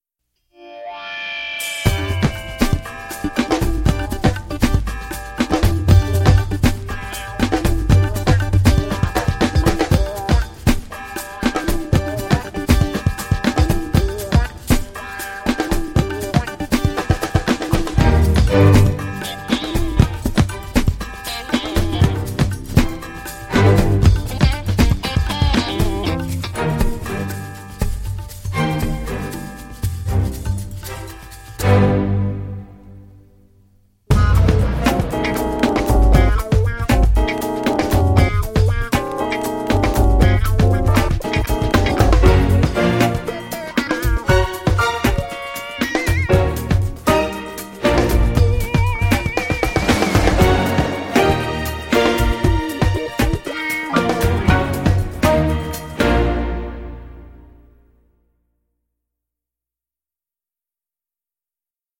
Excellent score pop-rock
son penchant pour les percussions les plus diverses